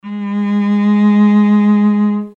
interactive-fretboard / samples / cello / Gs3.mp3
Gs3.mp3